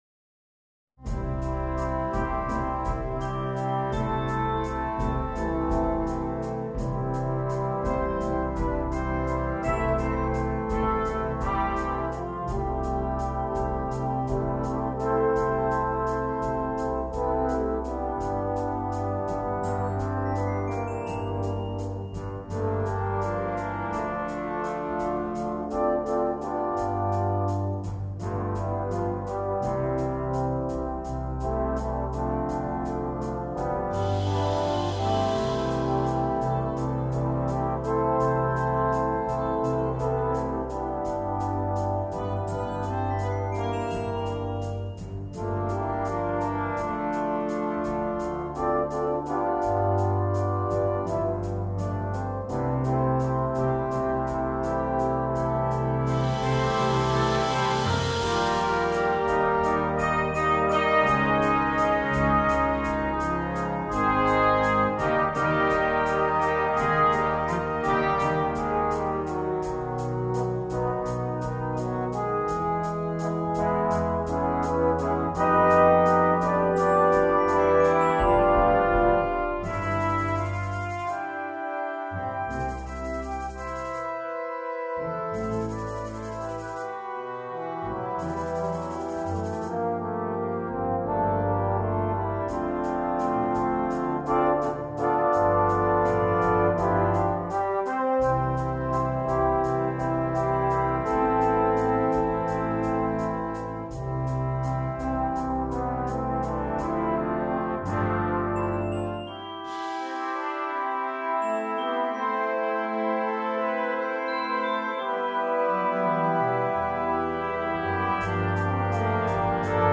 sans instrument solo
musique de film
facile